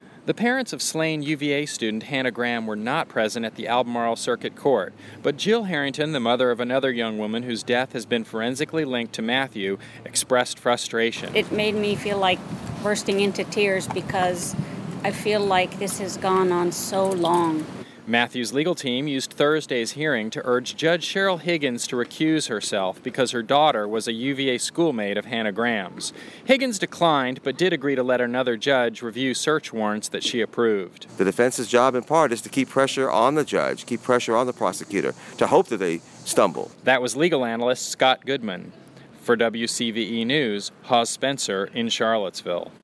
For WCVE News